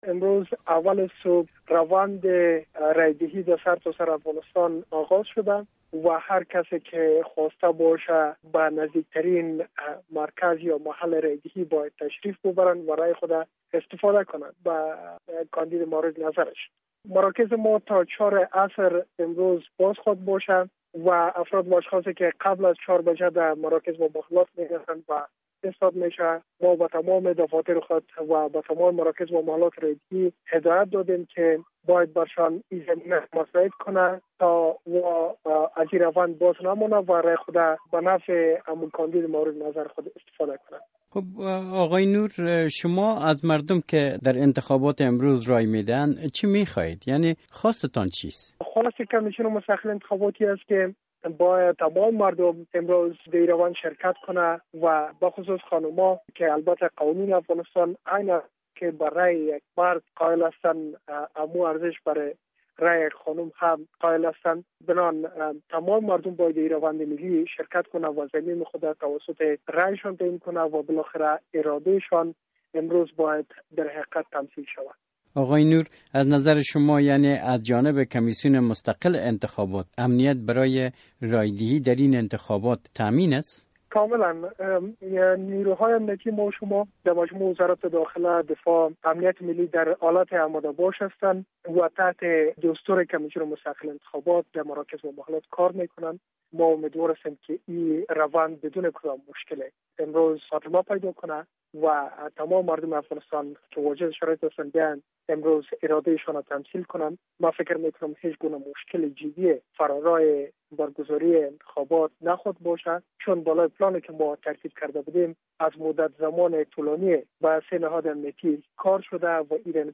مصاحبه در مورد آمادگی های کمیسیون مستقل انتخابات افغانستان